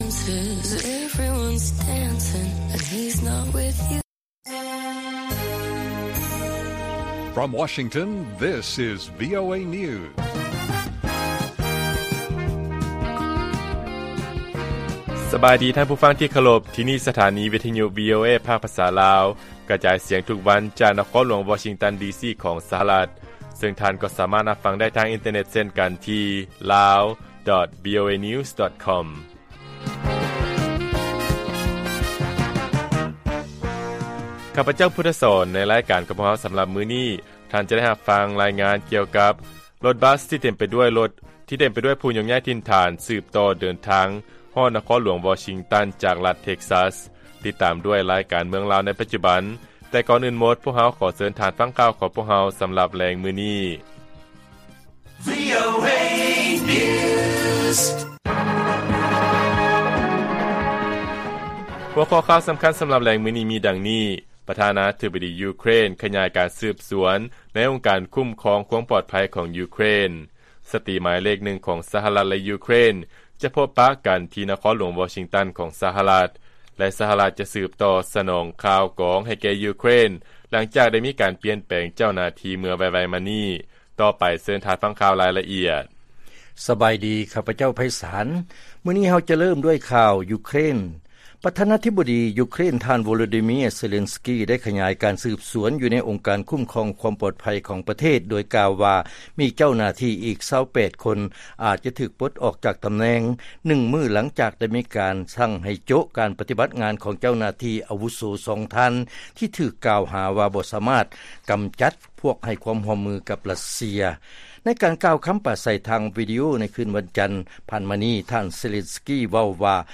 ລາຍການກະຈາຍສຽງຂອງວີໂອເອ ລາວ: ປະທານາທິບໍດີຢູເຄຣນ ຂະຫຍາຍການສືບສວນ ຢູ່ໃນອົງການຄຸ້ມຄອງຄວາມປອດໄພ ຂອງປະເທດ